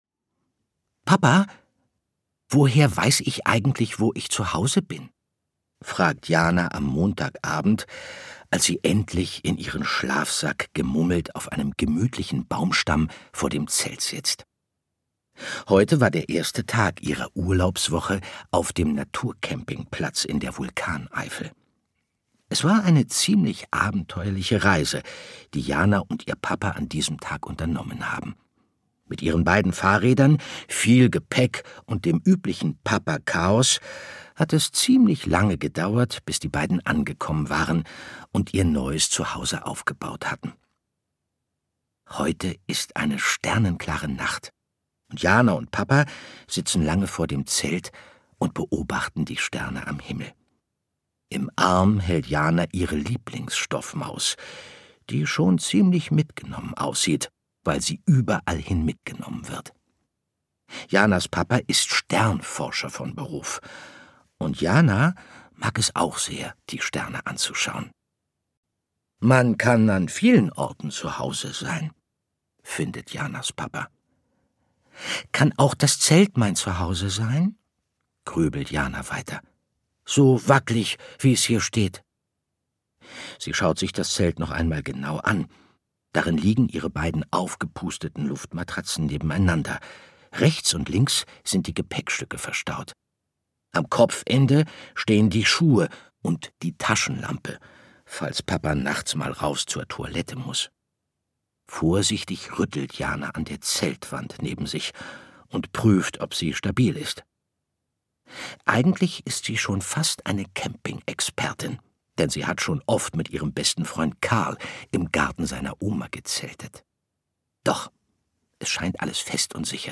Ein spannendes MINT-Hörsachbuch, das auch philosophische Fragen streift.
Mit warmen Timbre schlüpft er in die Rolle des gewitzten Physikers und sorgt für himmlische Kuschelstimmung.
Kekskrümel unterm Himmelszelt. Wie entstand unsere Erde? Gelesen von: Andreas Fröhlich